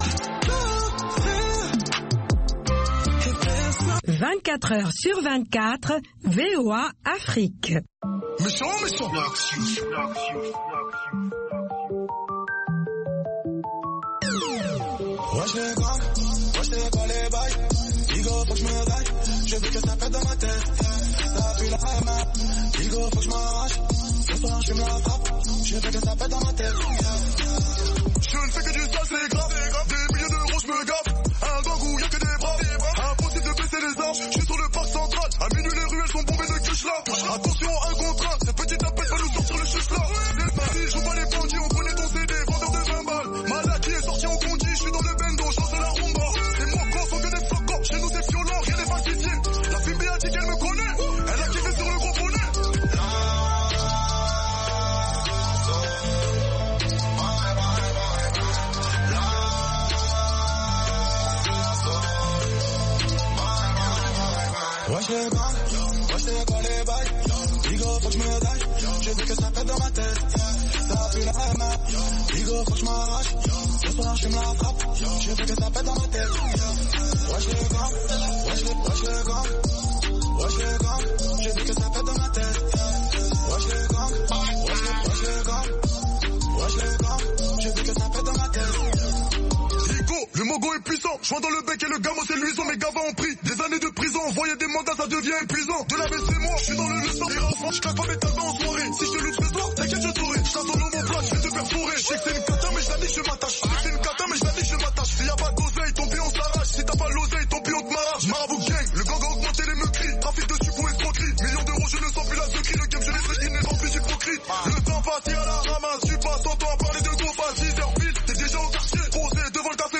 Bulletin d’information de 15 heures
Bienvenu dans ce bulletin d’information de VOA Afrique.